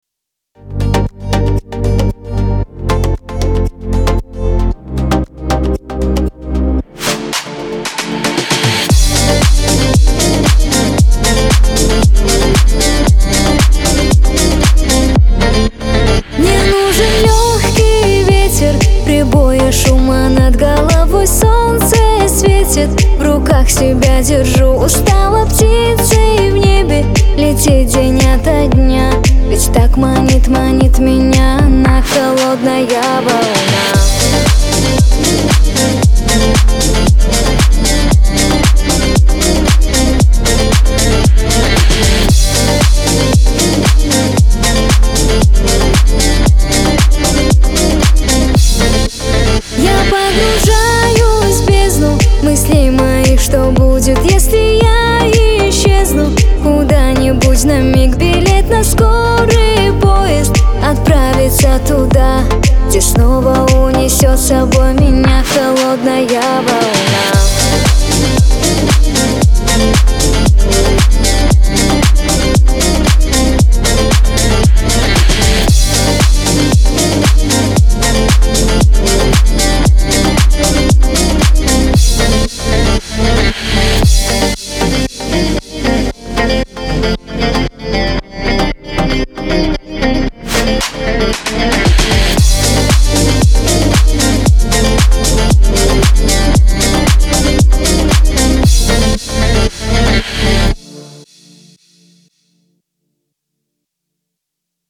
pop
dance